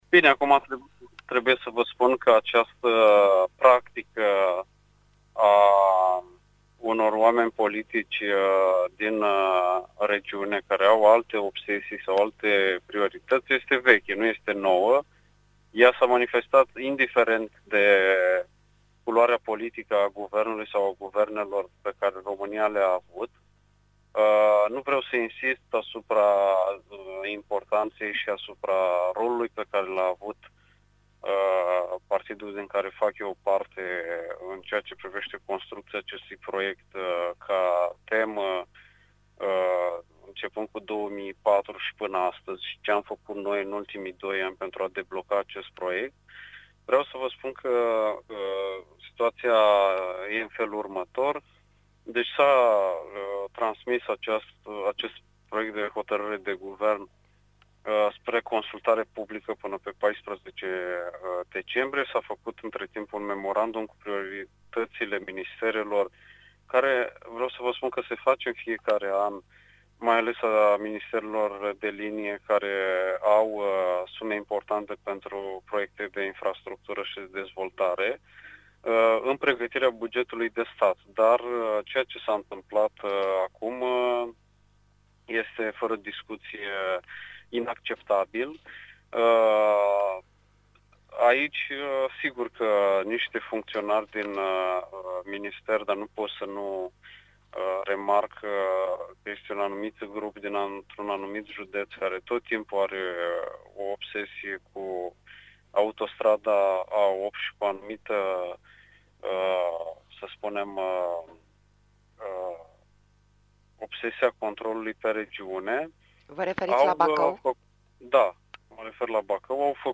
Discuția cu Alexandru Muraru, președintele PNL Iași, poată fi urmărită aici: